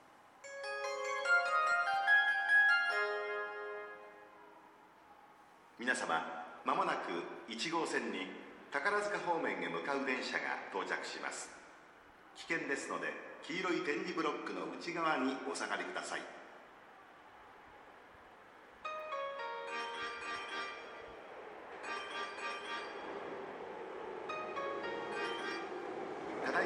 この駅では接近放送が設置されています。
接近放送急行　宝塚行き接近放送です。